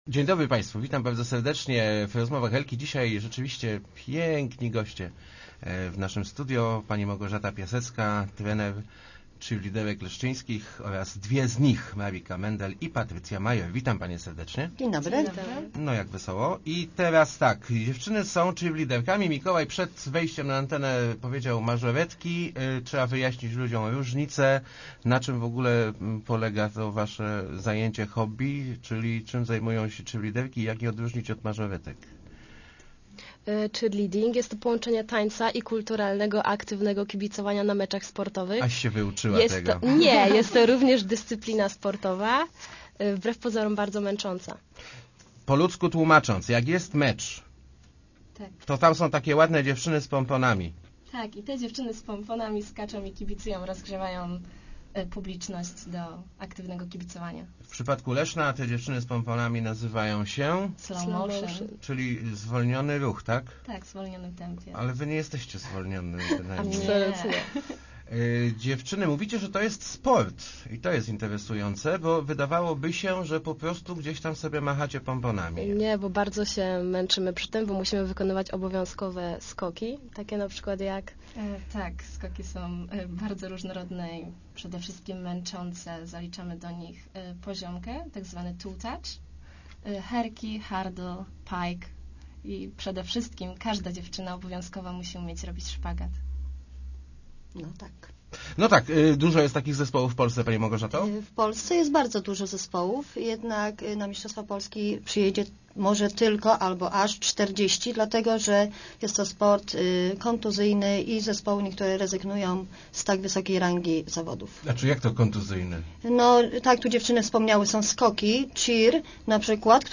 Start arrow Rozmowy Elki arrow Cheerleaderki też potrzebują kibiców